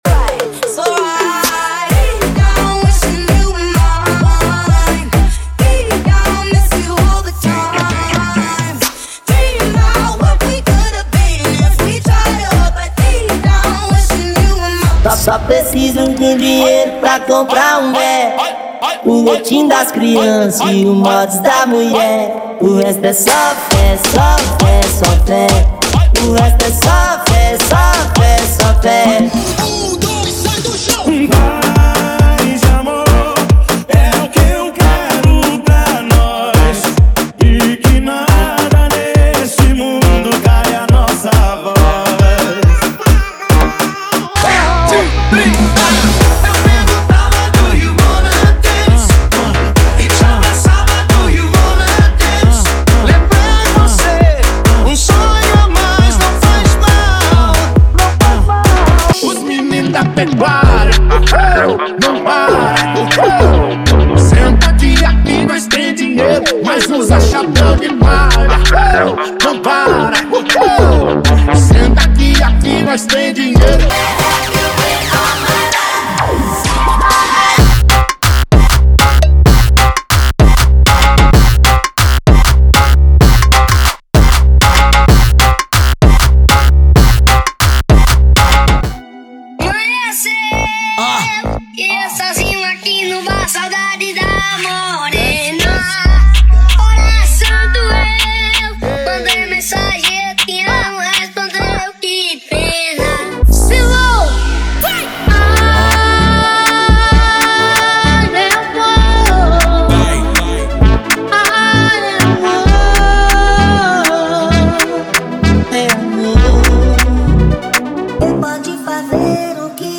• Funk Light e Funk Remix = 100 Músicas
• Sem Vinhetas
• Em Alta Qualidade